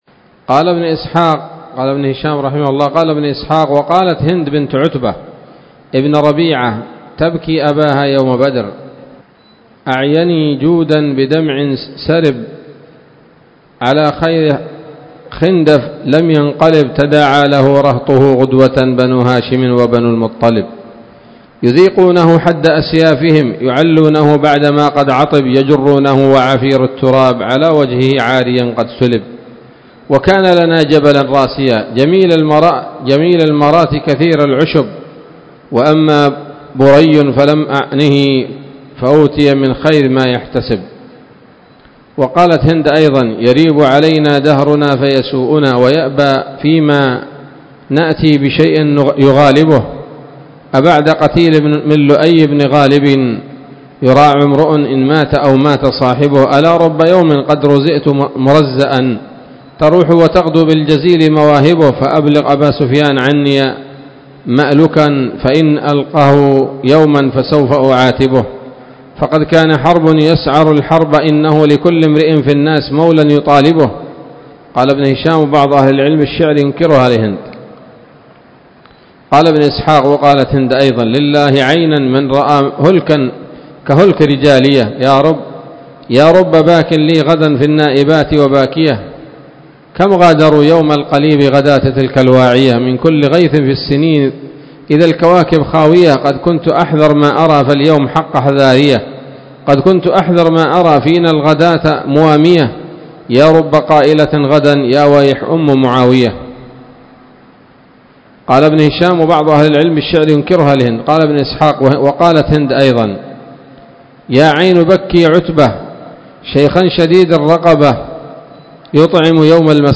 الدرس السادس والأربعون بعد المائة من التعليق على كتاب السيرة النبوية لابن هشام